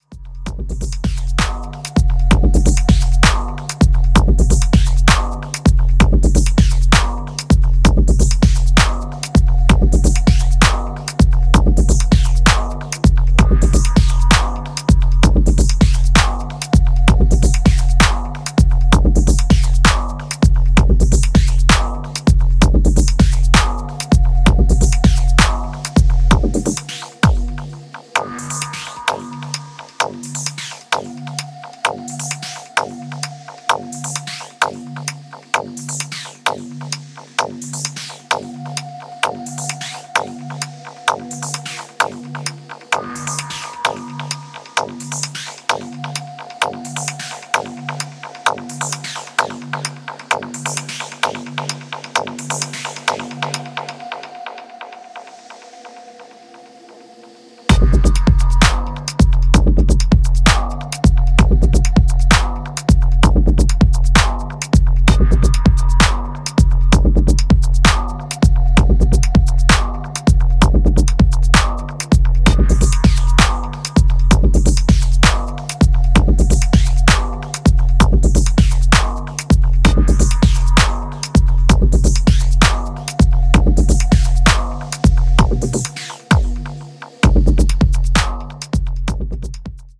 TOP >Vinyl >Grime/Dub-Step/HipHop/Juke